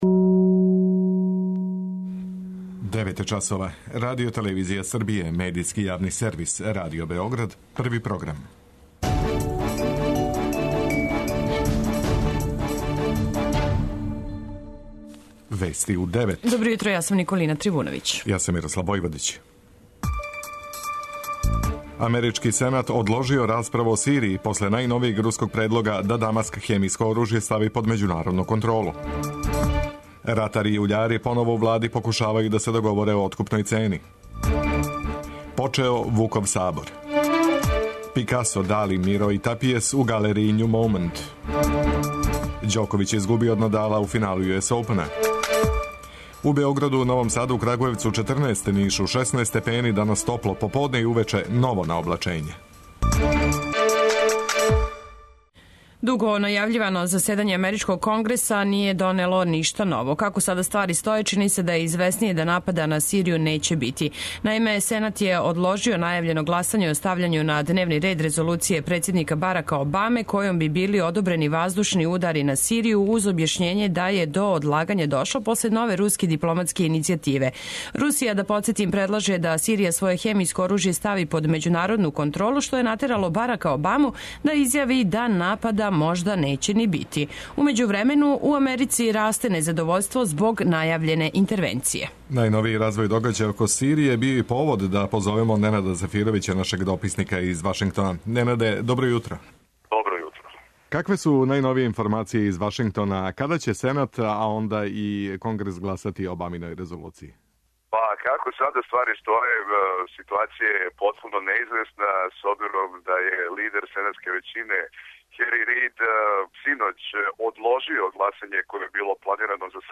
Вести уређују и воде